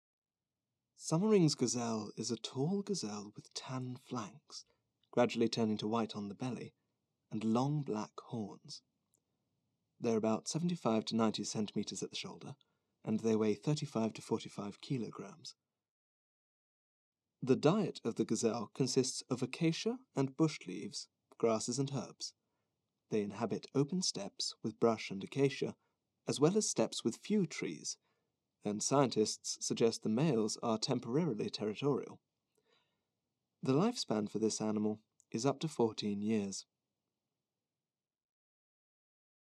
Native British, radio, video game experience
englisch (uk)
Sprechprobe: Industrie (Muttersprache):
I am a British actor, RP, experienced in voice over.